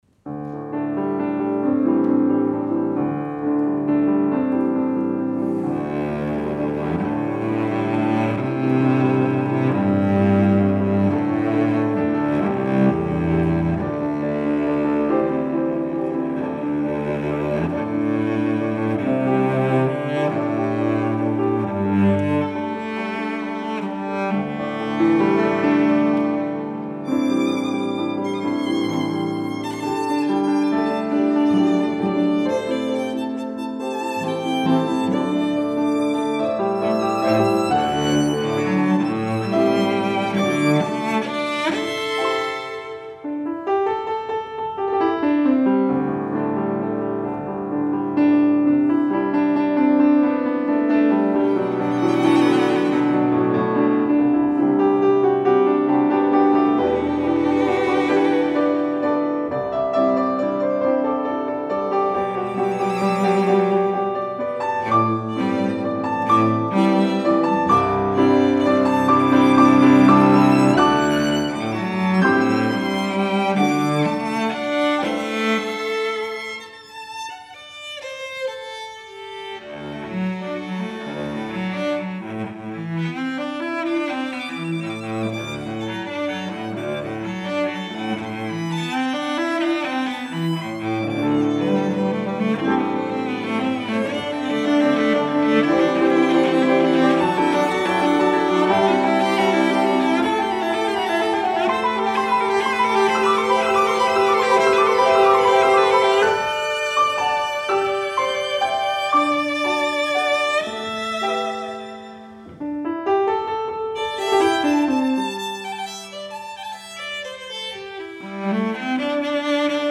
for Piano Trio (2015)
violin
cello
piano.
This is gentle music -- both flowing and calm.